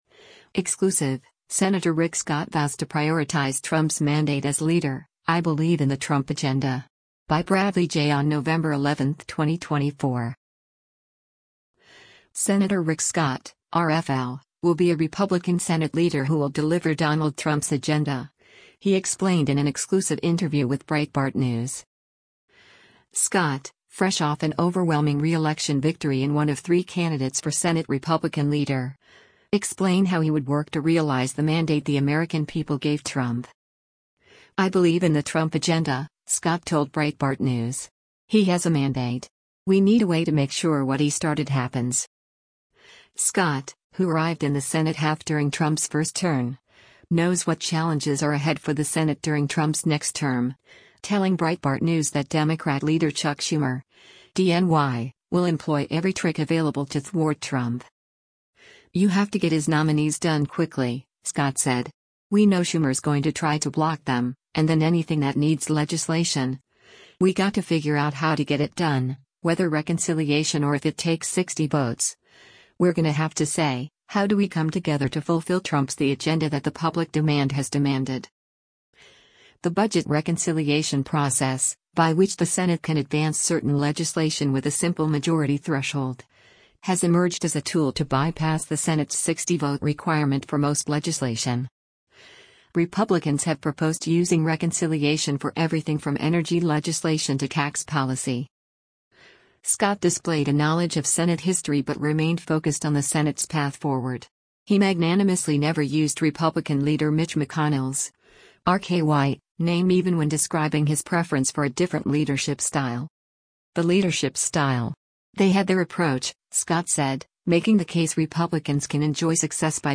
Sen. Rick Scott (R-FL) will be a Republican Senate leader who will deliver Donald Trump’s agenda, he explained in an exclusive interview with Breitbart News.